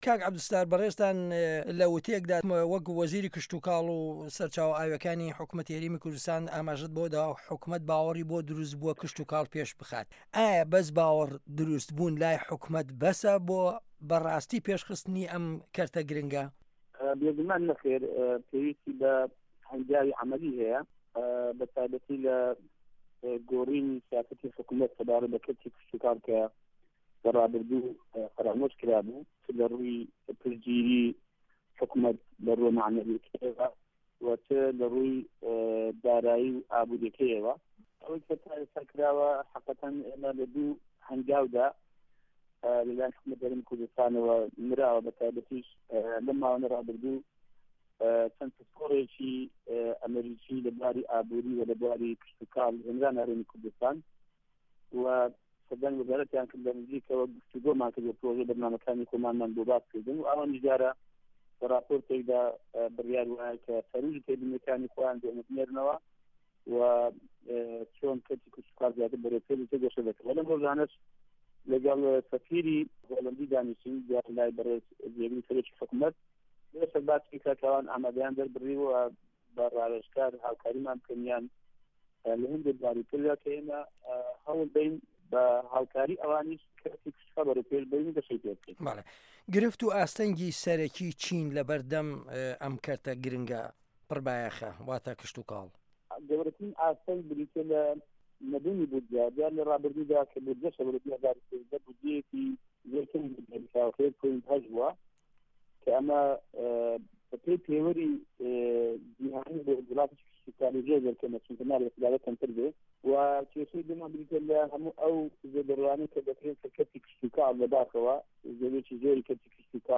وتوێژ لەگەڵ عەبدوسەتار مەجید